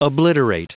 Prononciation du mot obliterate en anglais (fichier audio)
Prononciation du mot : obliterate